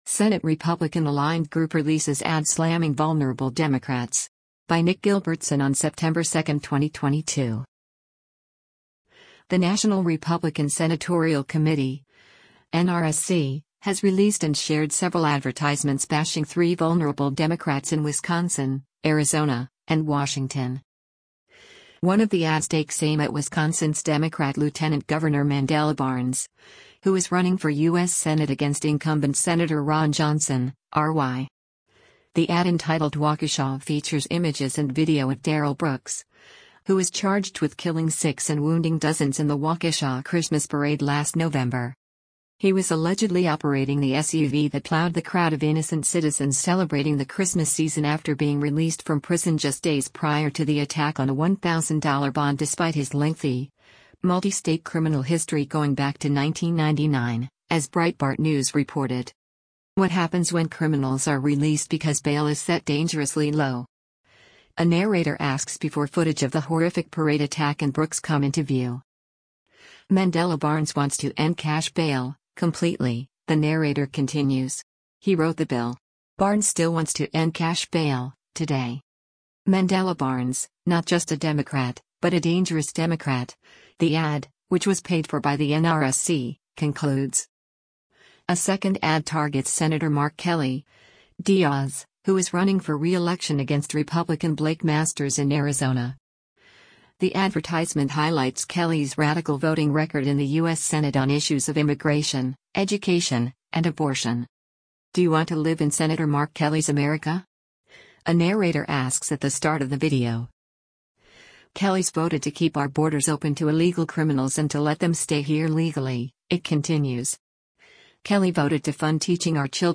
Ad Against Mark Kelly
“Do you want to live in Senator Mark Kelly’s America?” a narrator asks at the start of the video.